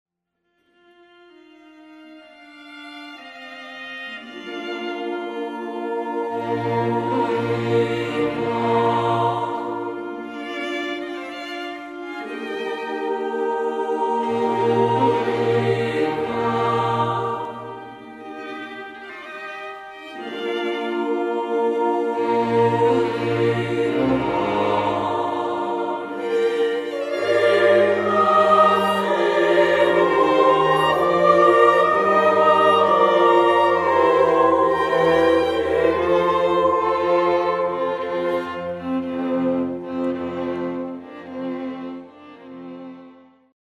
per soli, coro femminile, orchestra d'archi e basso continuo